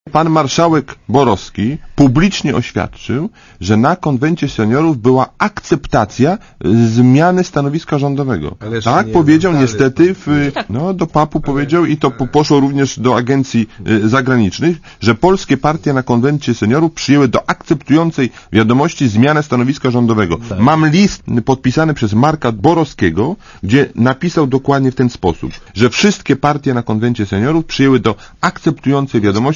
Czy kompromis w sprawie unijnej konstytucji jest realny i czy jest goda Sejmu na „zmiękczenie” polskiego stanowiska? O tym w Radiu Zet rozmawiali goście programu 7. Dzień Tygodnia.